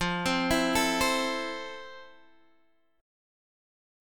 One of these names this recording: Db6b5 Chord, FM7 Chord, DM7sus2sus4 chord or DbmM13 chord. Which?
FM7 Chord